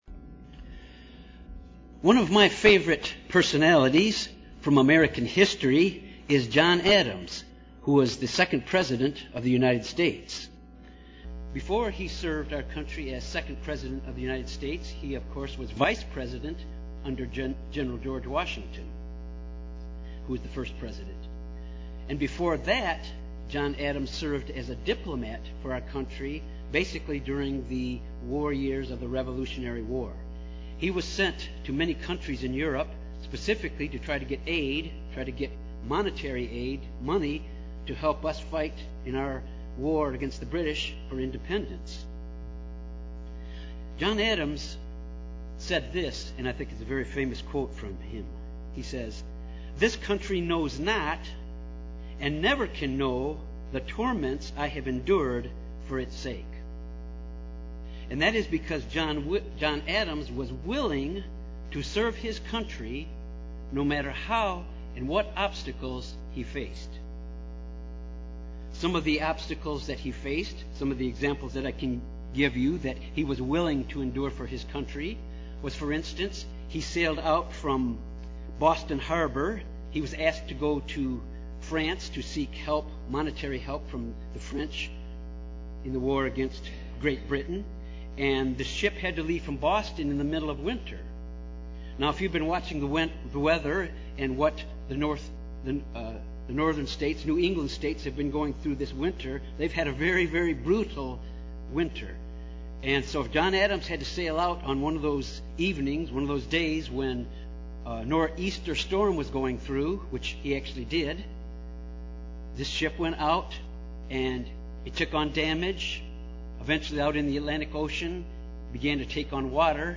Three aspects of practicing the fruit of Gentleness in our lives UCG Sermon Studying the bible?